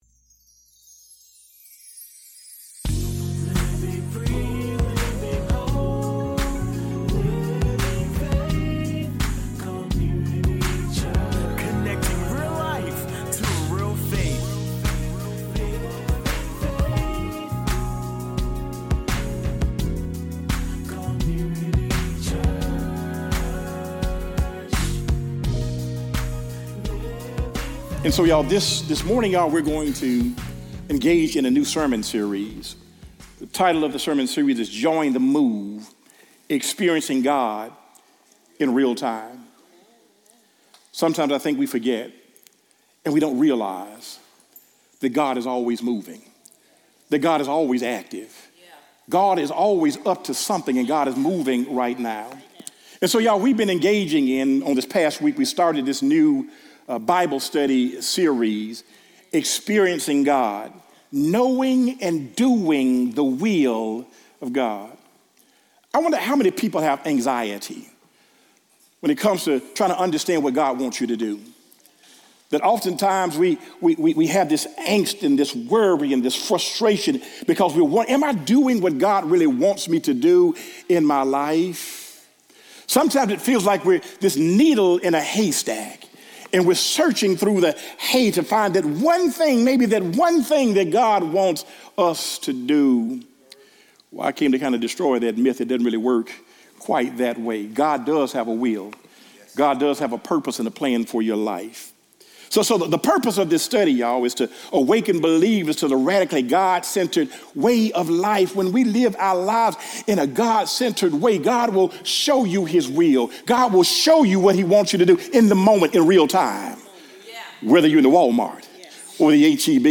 Audio Sermons | Living Faith Community Church